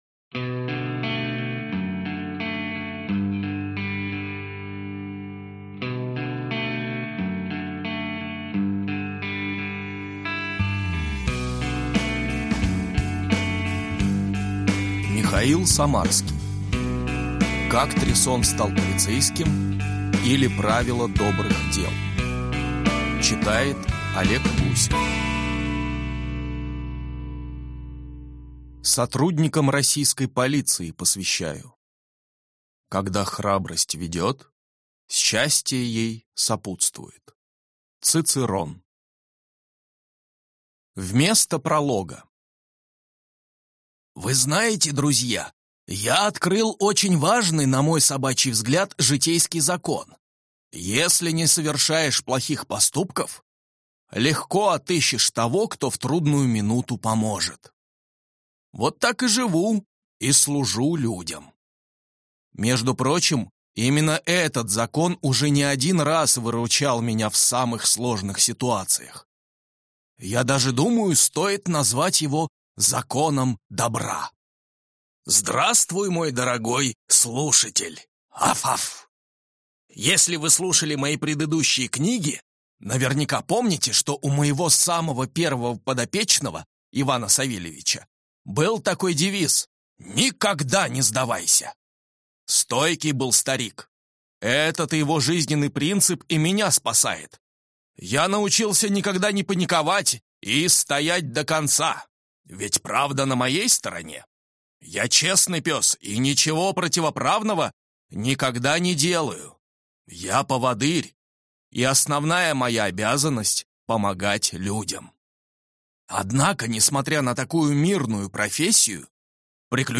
Аудиокнига Как Трисон стал полицейским, или Правила добрых дел | Библиотека аудиокниг